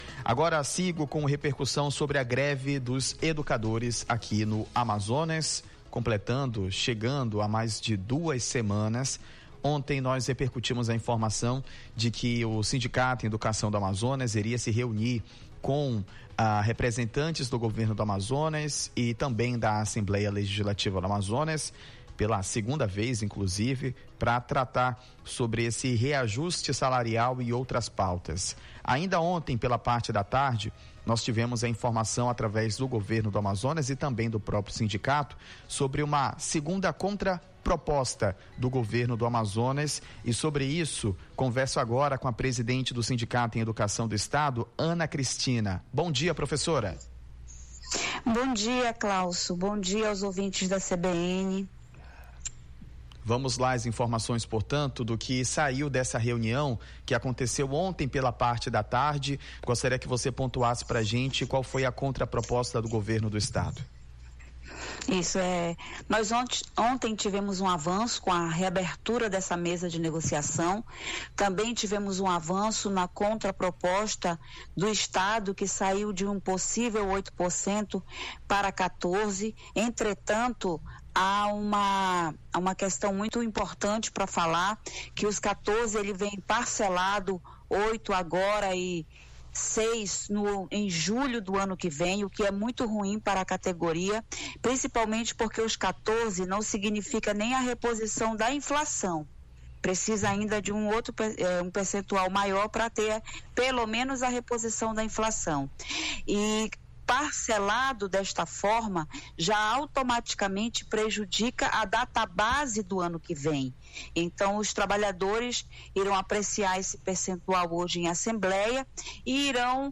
greve-sinteam-entrevista.mp3